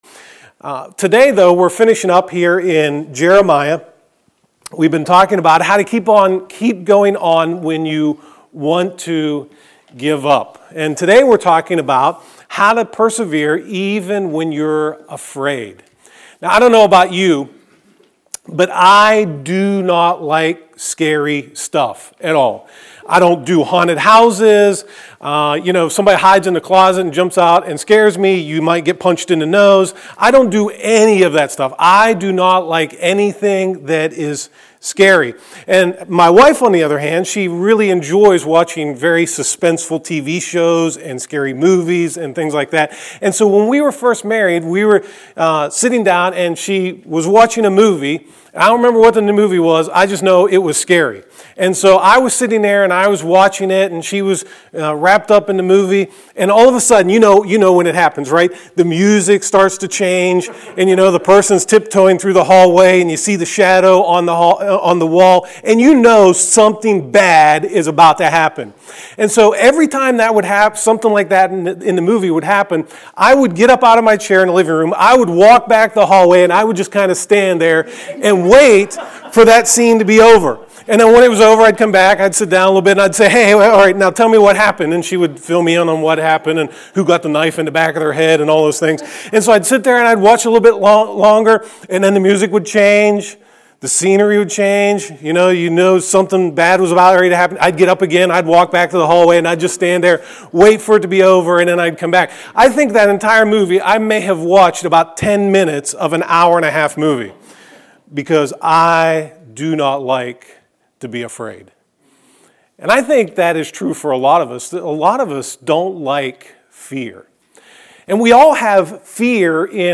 2018 Persevere When You are Afraid Preacher